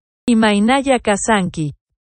Imaynalla kasanki = IMA-EE-NA-ELA KA-SHAN-KEY